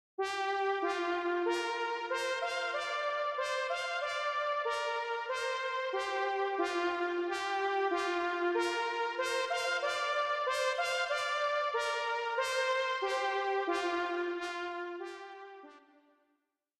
(с сильной реверберацией). Похоже на «правду», но FM-природа заметно ощущается, особенно на трудном для FM синтеза тембре фортепиано.
04trumpet.mp3